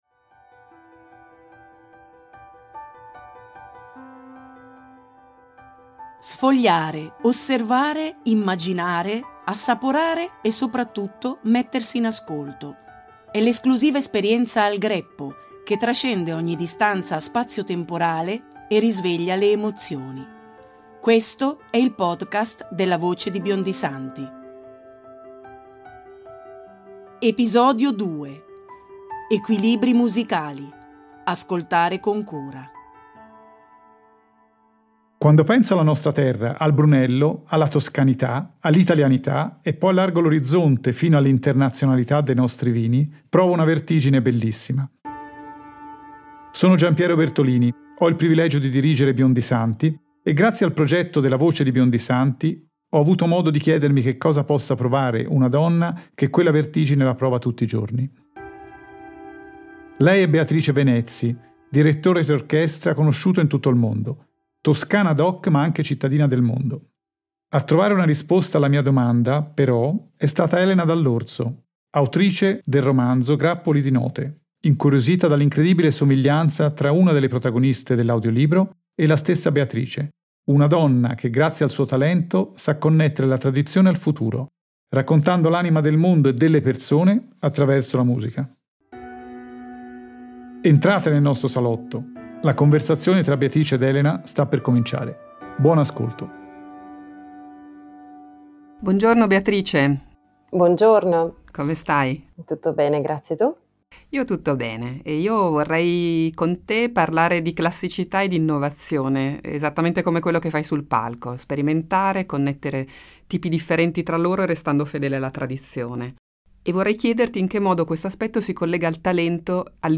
Conversazioni